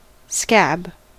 Ääntäminen
Ääntäminen US
IPA : /skæb/